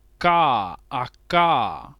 17 k consonant plosive velar unvoiced [
voiceless_velar_plosive.wav